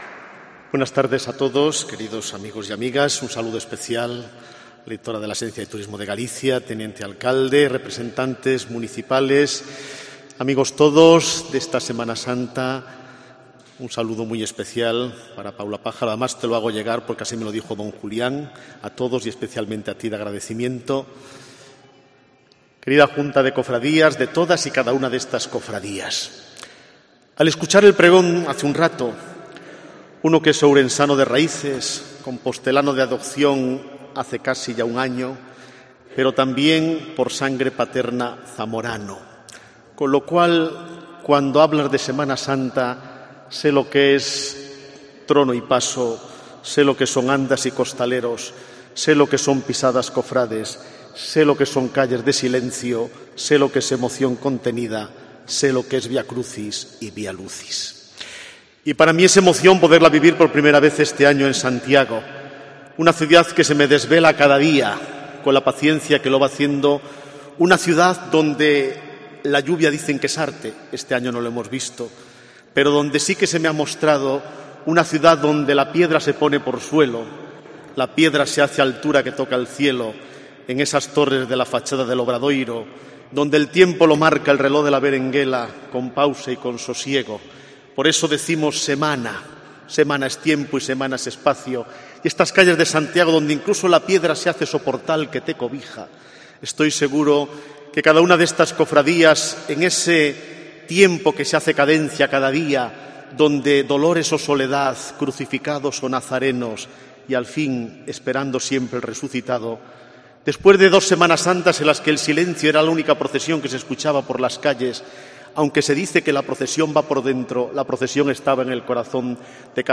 Obispo Auxiliar de Santiago durante el pregón